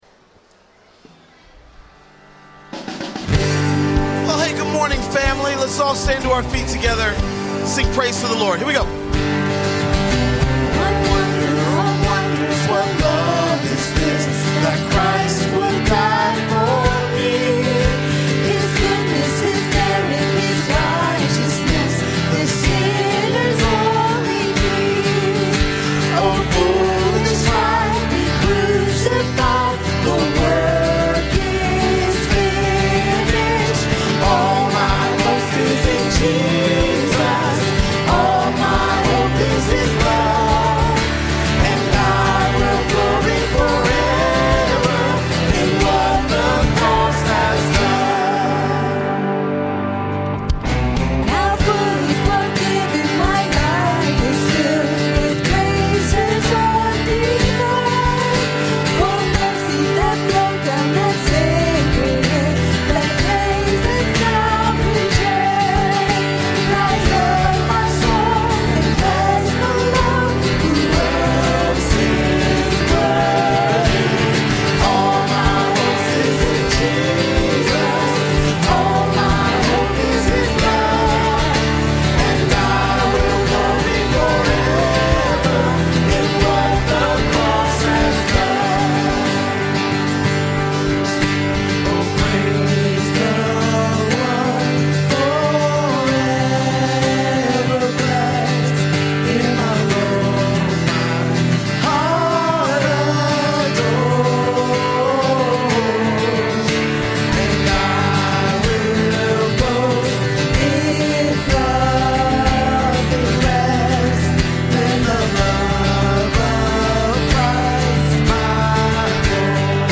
" Shepherds Heart " Sermon Notes